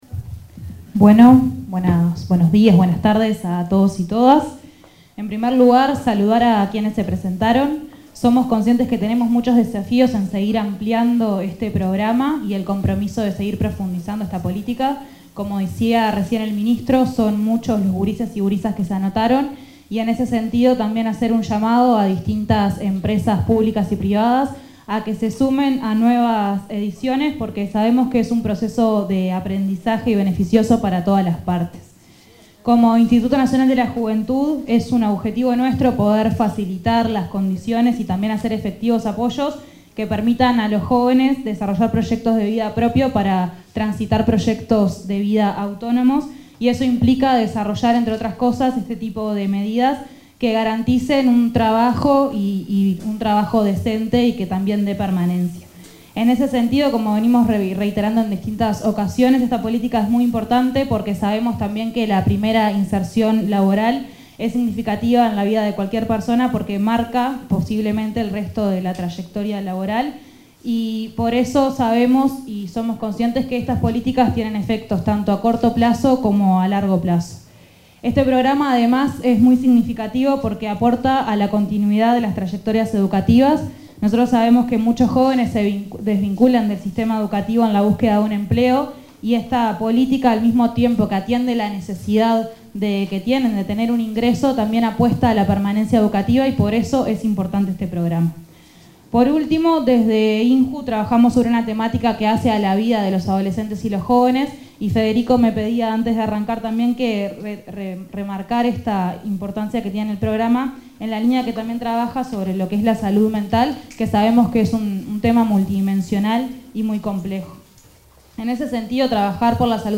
Palabras de autoridades en sorteo de 14.ª edición del programa Yo Estudio y Trabajo
Palabras de autoridades en sorteo de 14.ª edición del programa Yo Estudio y Trabajo 29/10/2025 Compartir Facebook X Copiar enlace WhatsApp LinkedIn El director nacional de Empleo, Federico Araya, y la directora del Instituto Nacional de la Juventud, Eugenia Godoy, se expresaron acerca de las características de la edición de 2025 del programa Yo Estudio y Trabajo, durante el sorteo realizado este 29 de octubre en la sede del Ministerio de Trabajo y Seguridad Social.